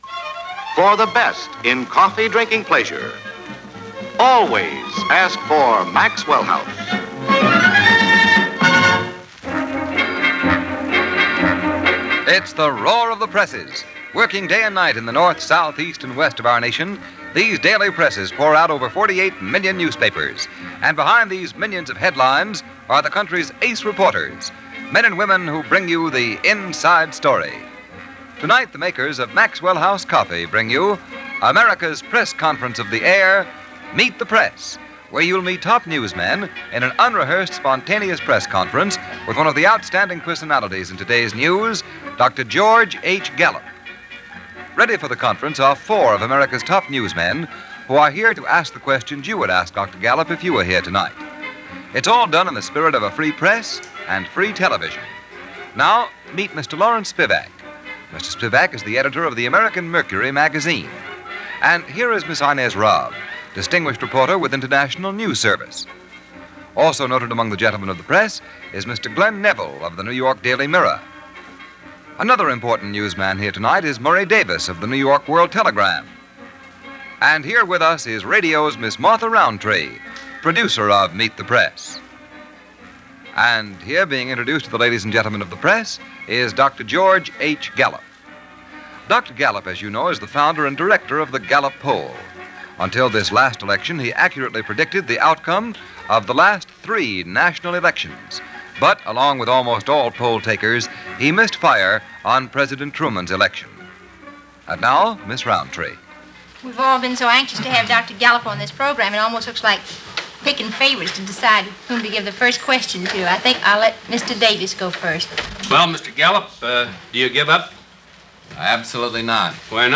George H. Gallup and His wildly unpredictable Poll in 1948 - Interview from Meet the Press - November 14, 1948 - Past Daily Reference Room.